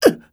SFX player_damage.wav